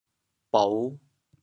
潮州 dua7 bou5 cao2 潮阳 dua7 bou5 cao2 潮州 0 1 2 潮阳 0 1 2